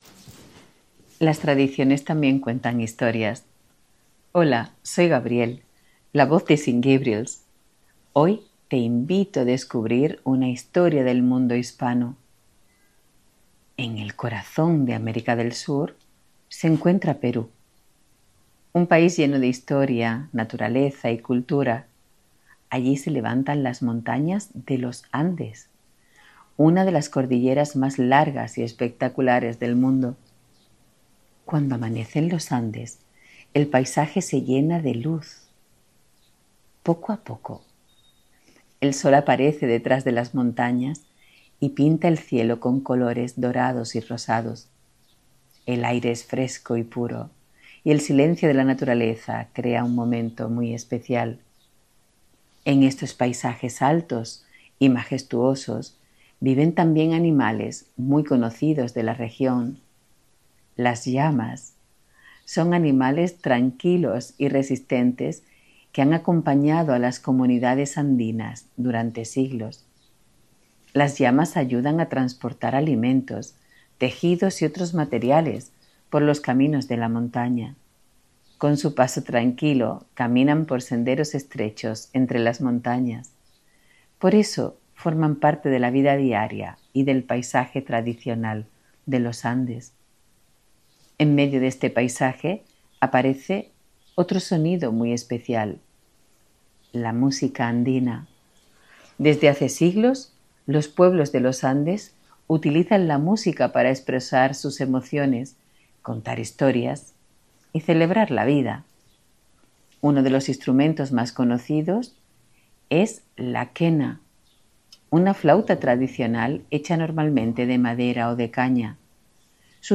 una narradora cálida, cercana y elegante que cada semana nos acompaña con un cuento, una fábula o una historia cultural del mundo hispano.